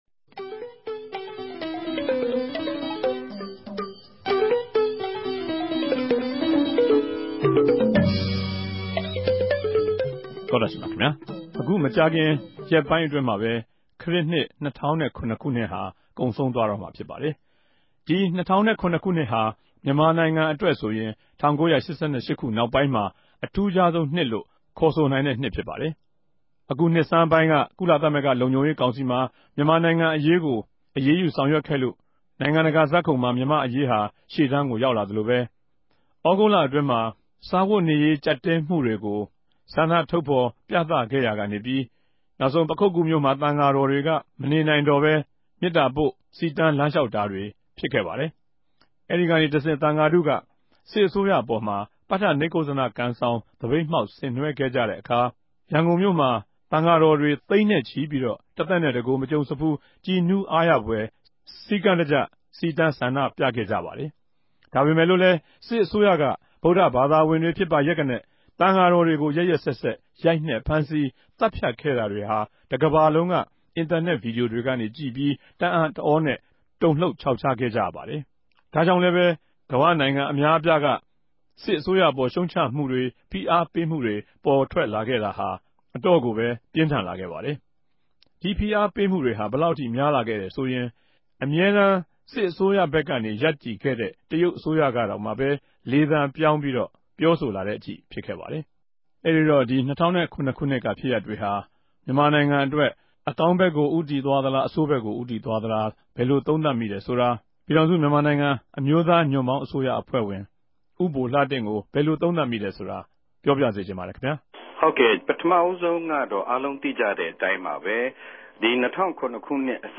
အခုတပတ် အာအက်ဖ်အေ တနဂဿေိံြနေႚ စကားဝိုင်းအစီအစဉ်မြာ မုကာခင်ကုန်ဆုံးတော့မဲ့ ၂၀၀၇ခိုံြစ်ဟာ ူမန်မာိံိုင်ငံအတြက် ဘယ်လိုကောင်းကဵိြး၊ ဆိုးကဵိြးတြေ ရြိစေခဲ့တယ်ဆိုတာ ဆြေးေိံြးသုံးသပ်ထားုကပၝတယ်။